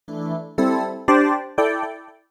Level_Up.mp3